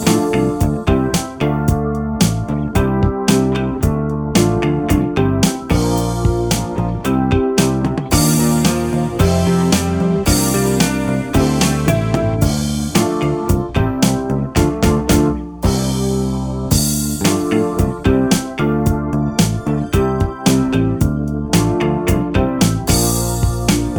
Minus Sax Pop (1960s) 3:20 Buy £1.50